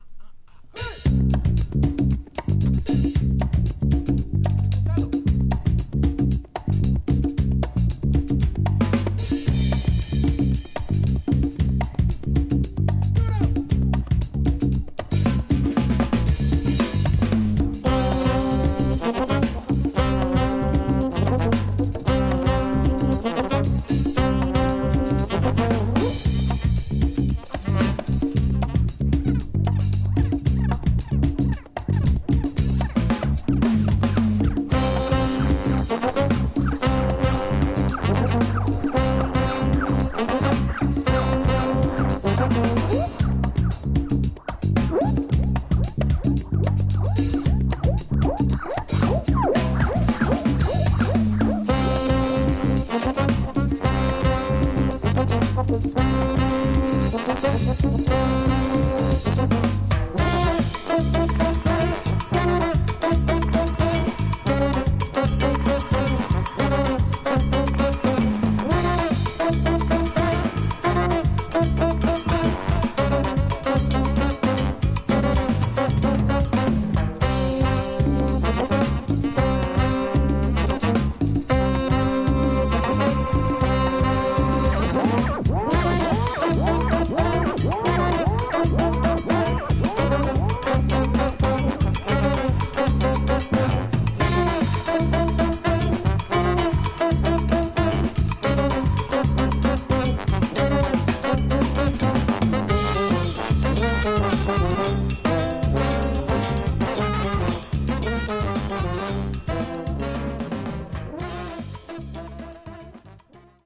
Tags: Brass , Psicodelico , Colombia , Bogotá